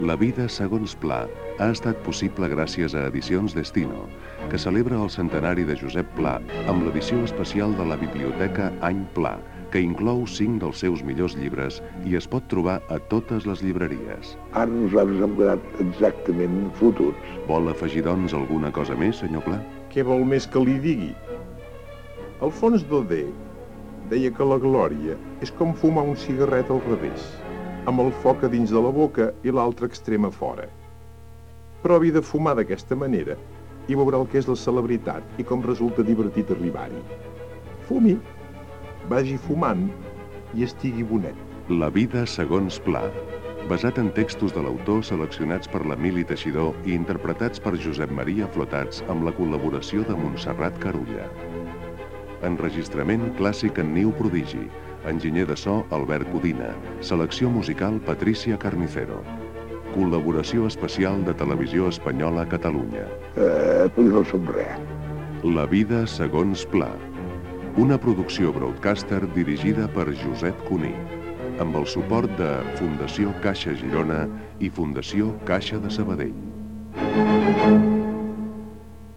Especial emès amb motiu del centenari del naixement de l'escriptor Josep Pla. Paraules de Josep Pla. Crèdits finals del programa